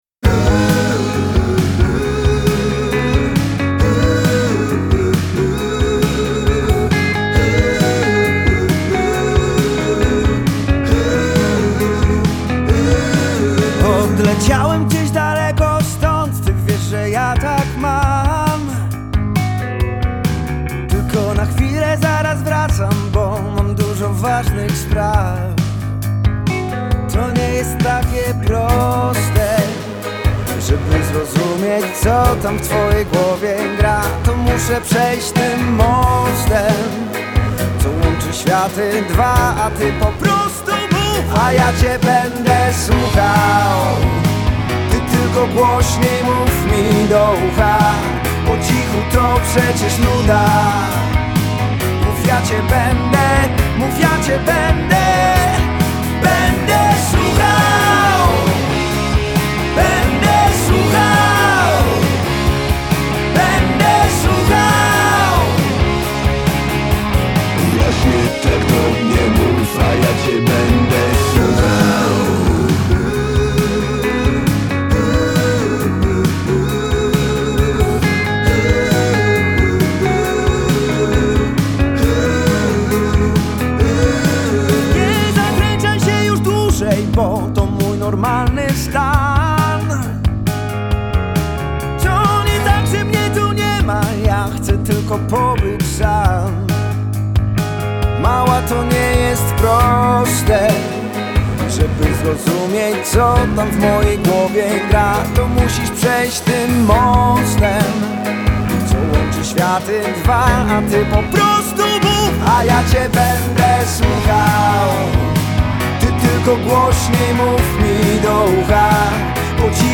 Najnowszy, energiczny singiel
energicznym i rytmicznym popowym singlem